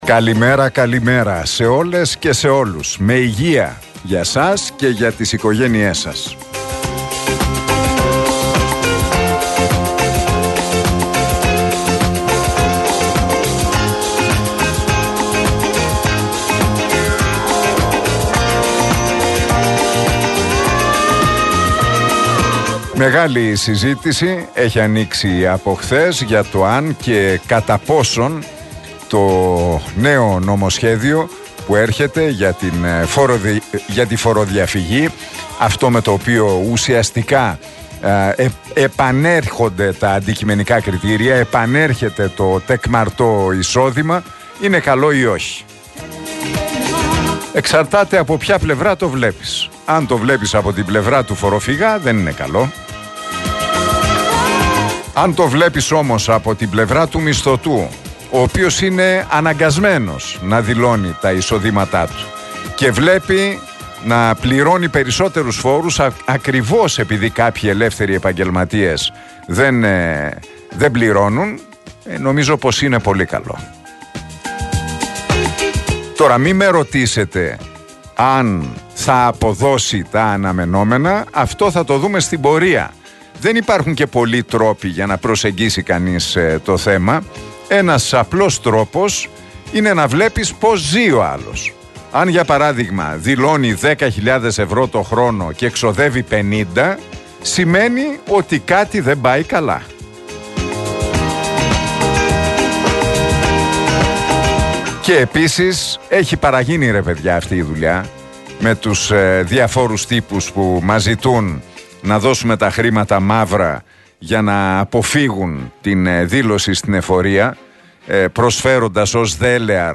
Ακούστε το σχόλιο του Νίκου Χατζηνικολάου στον RealFm 97,8, την Τετάρτη 1 Νοεμβρίου 2023.